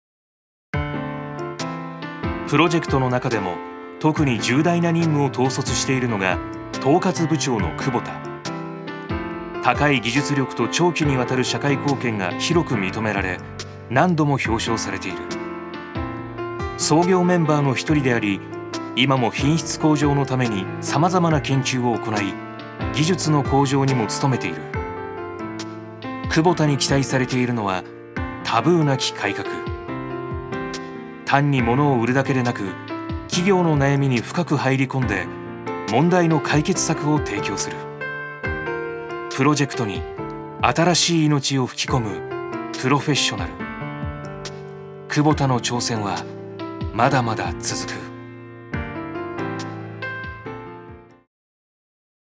所属：男性タレント
ナレーション６